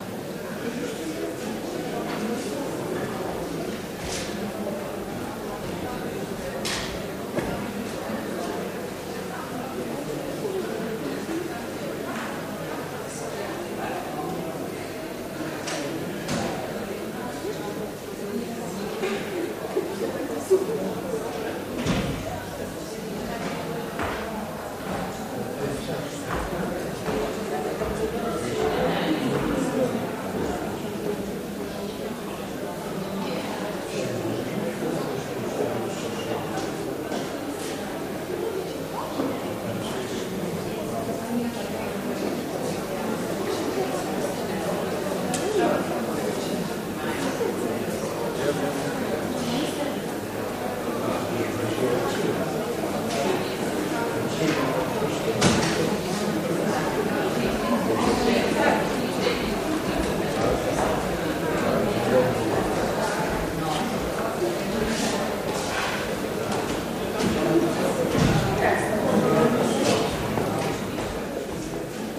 Lobby Walla Small Murmurs